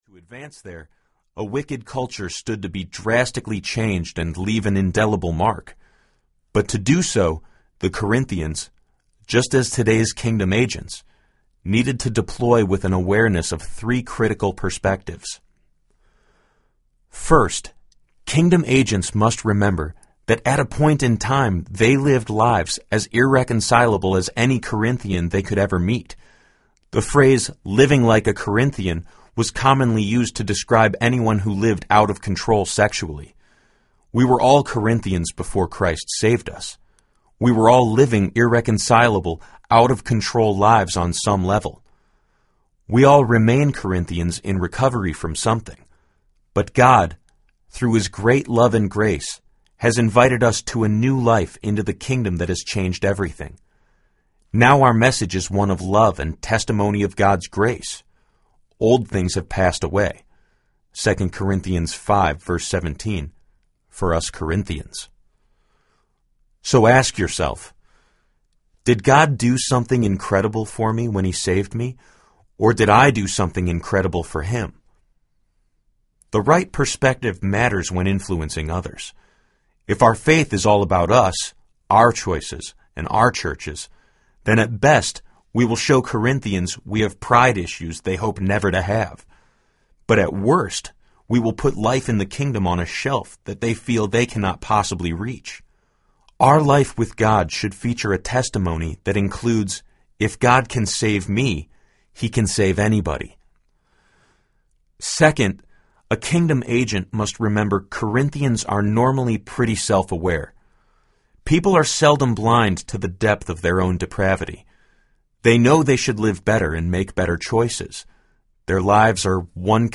Subversive Kingdom Audiobook
Narrator
– Unabridged